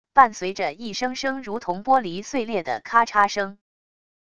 伴随着一声声如同玻璃碎裂的咔嚓声wav音频